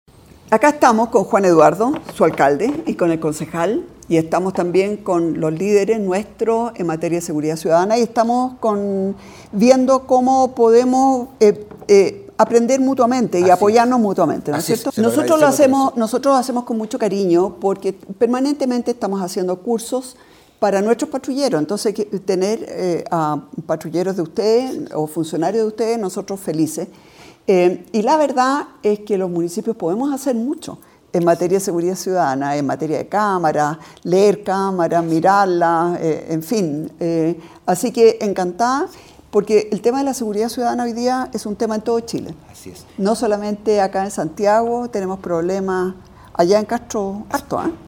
En tanto, la alcaldesa Evelyn Matthei destacó la importancia de esta colaboración intermunicipal:
EVELYN-MATHEI.mp3